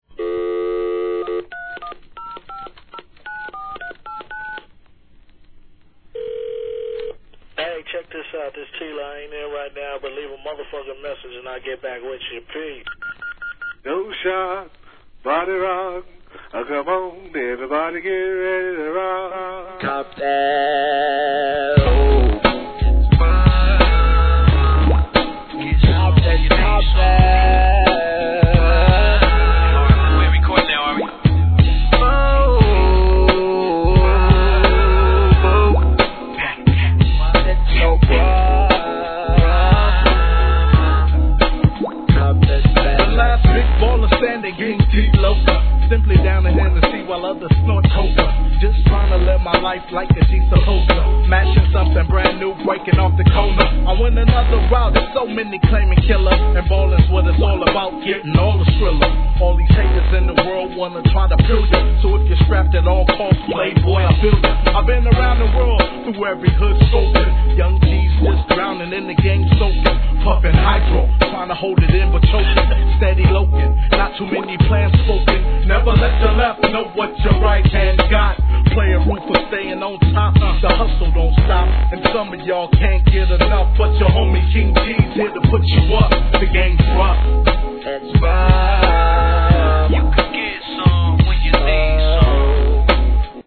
G-RAP/WEST COAST/SOUTH
メロ〜なネタ使いにTALK BOX炸裂のMIXでもお馴染みの逸品!